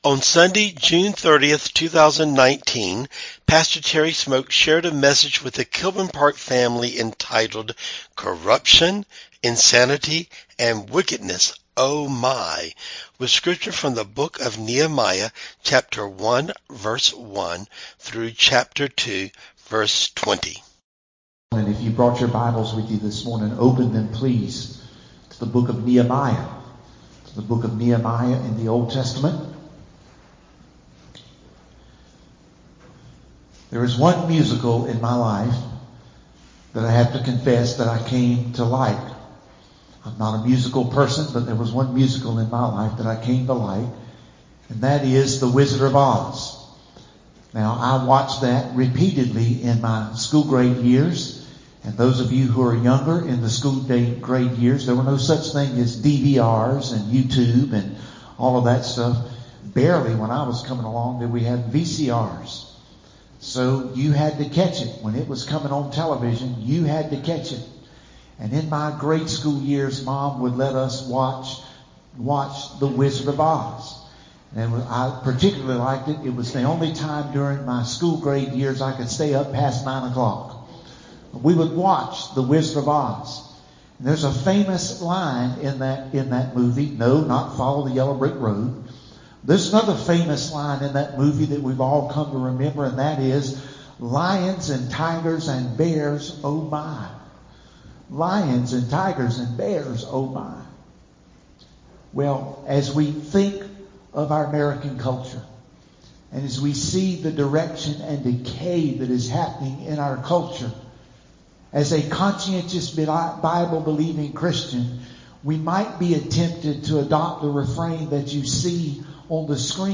6/30/19 Sermon